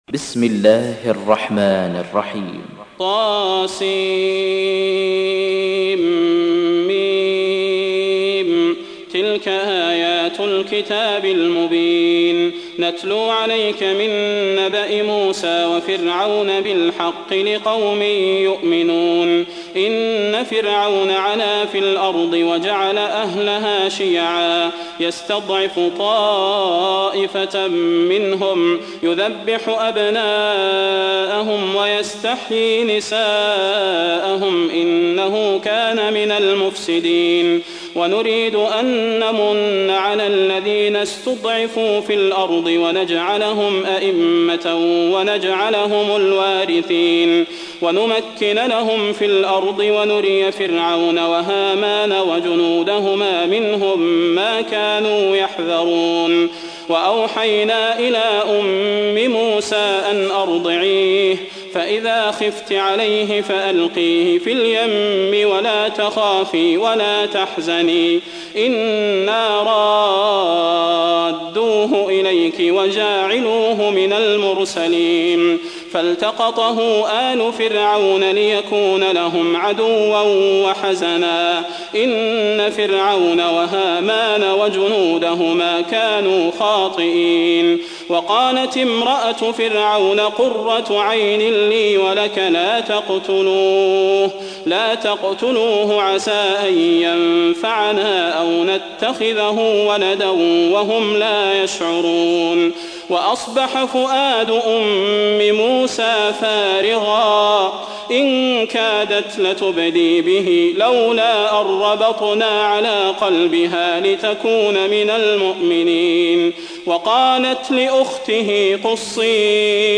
تحميل : 28. سورة القصص / القارئ صلاح البدير / القرآن الكريم / موقع يا حسين